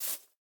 Minecraft Version Minecraft Version latest Latest Release | Latest Snapshot latest / assets / minecraft / sounds / block / bamboo / sapling_hit2.ogg Compare With Compare With Latest Release | Latest Snapshot
sapling_hit2.ogg